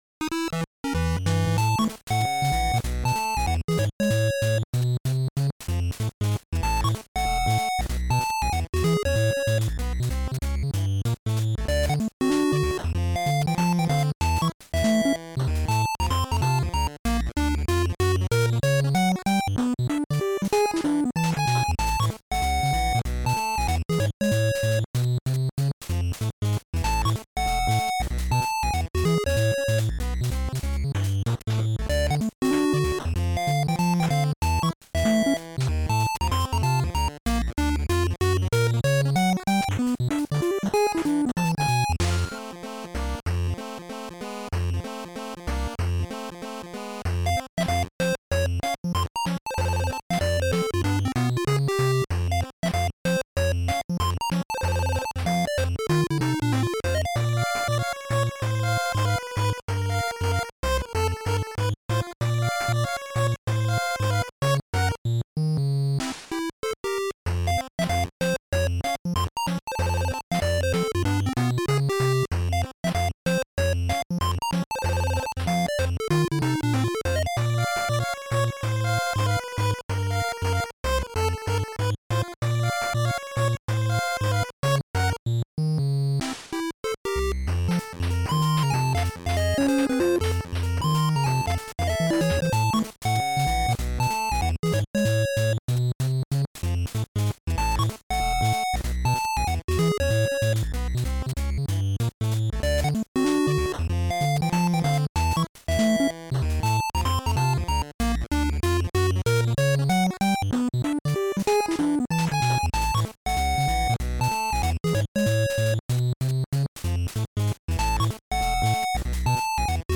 8-bit rendition